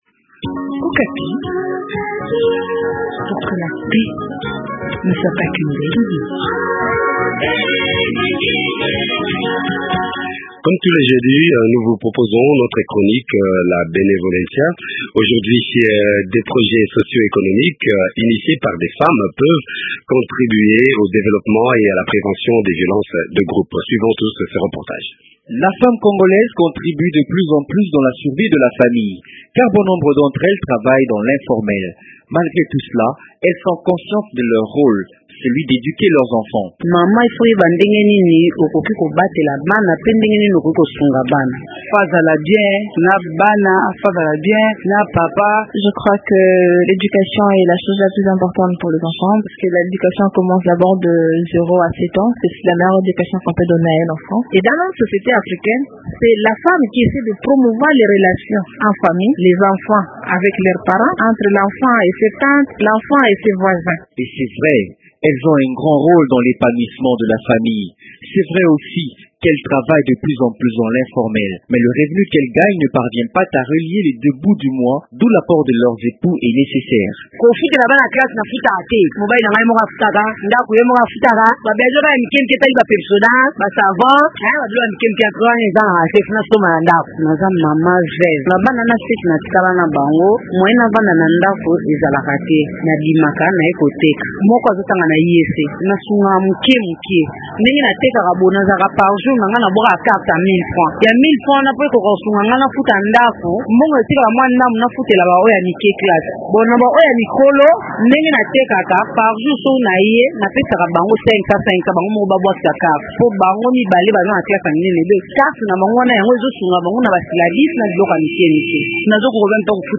Kinshasa : Chronique Benevolencja